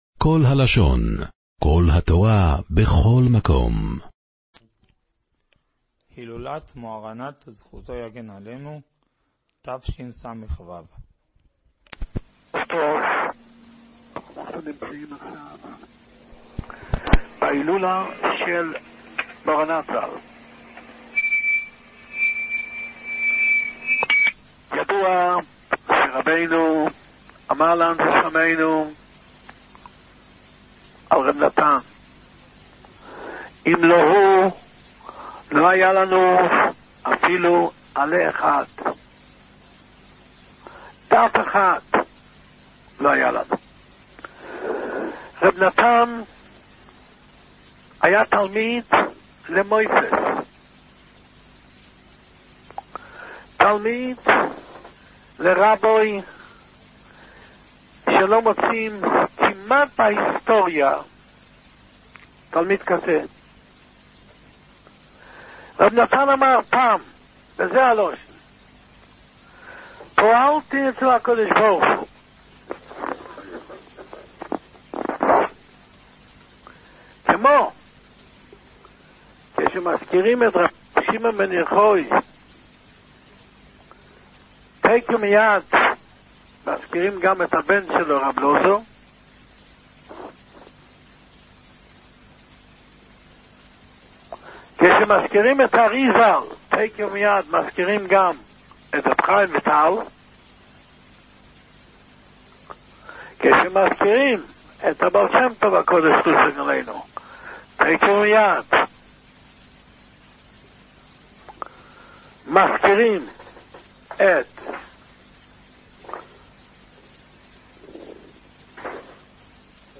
הילולת רבי נתן זי"ע. דרשה מעשרה בטבת תשס"ו.